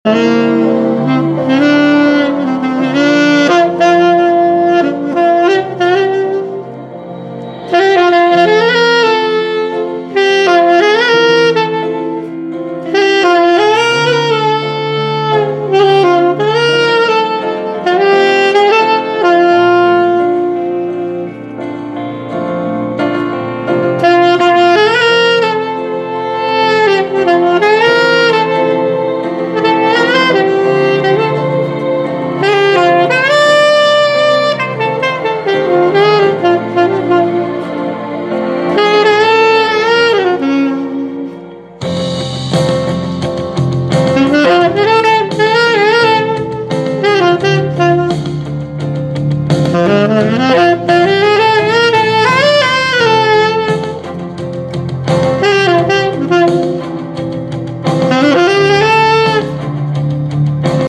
INSTRUMENTAL Sax Cover